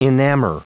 Prononciation du mot enamor en anglais (fichier audio)
enamor.wav